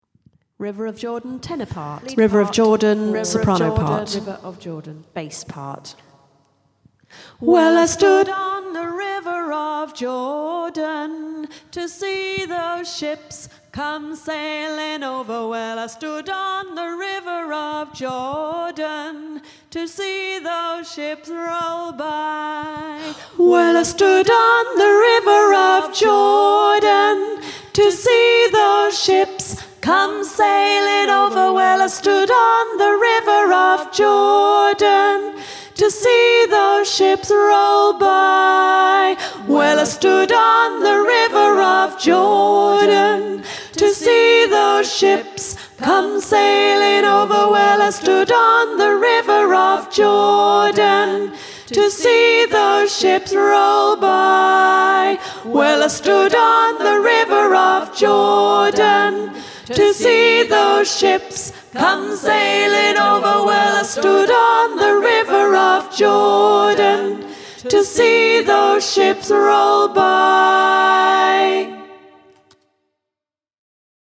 Genre: Choral.
river-of-jordan-sop.mp3